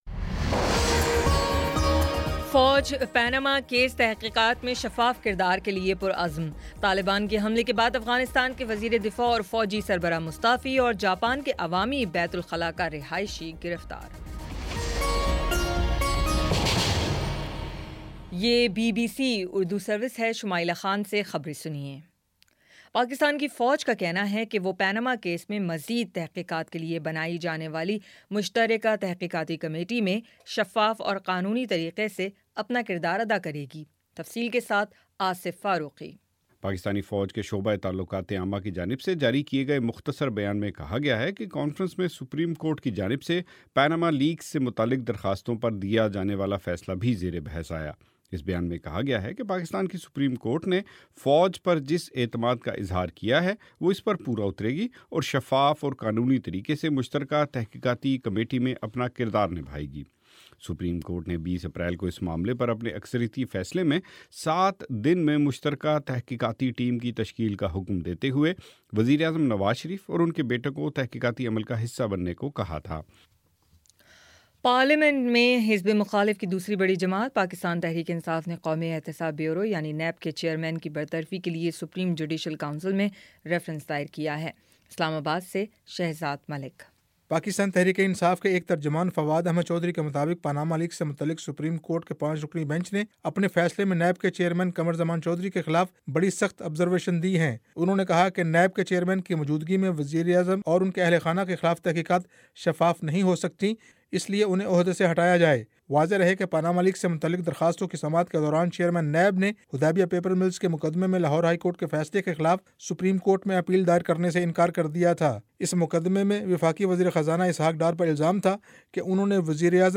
اپریل 24 : شام چھ بجے کا نیوز بُلیٹن